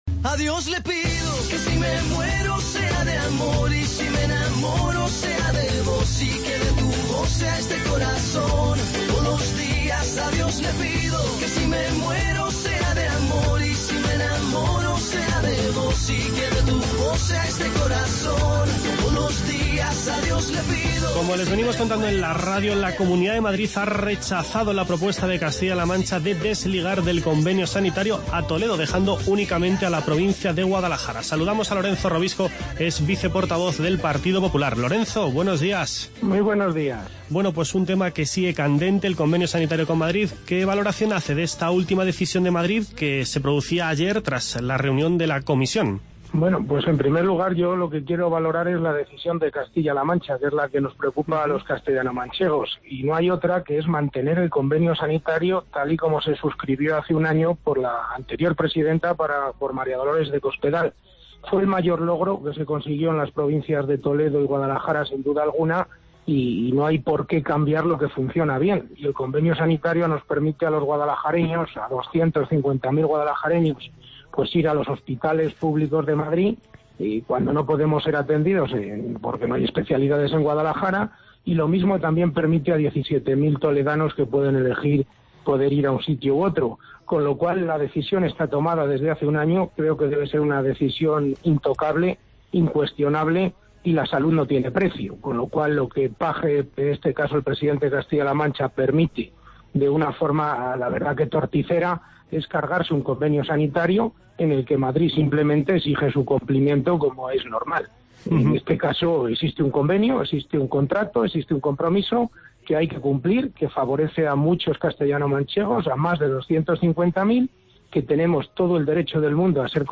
La Comunidad de Madrid ha rechazado la propuesta de Castilla-La Mancha de desligar del convenio sanitario a los municipios del norte de Toledo, dejando únicamente a la provincia de Guadalajara, por ser "inviable jurídicamente". Madrid da al Gobierno de Castilla-La Mancha hasta el 30 de abril para aclarar su posición. Es noticia de última hora que hoy analizamos en la sintonía de COPE con Lorenzo Robisco, viceportavoz del Partido Popular, y con Regina Leal, directora gerente del SESCAM (Servicio de Salud de Castilla-La Mancha).